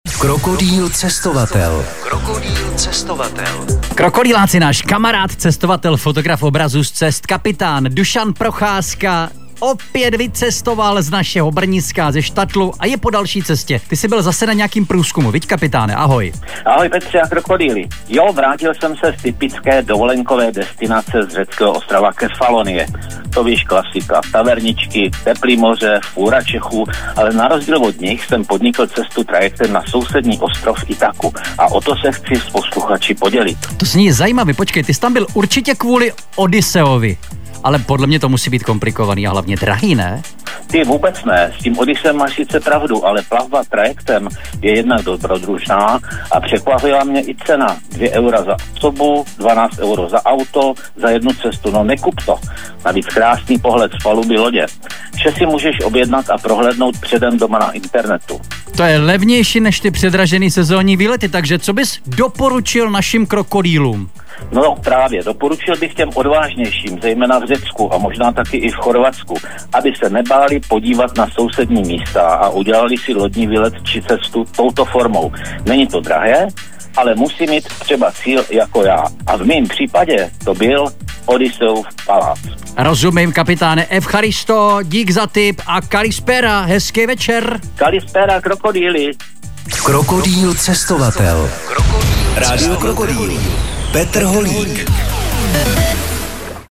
Spot pro rádio Krokodýl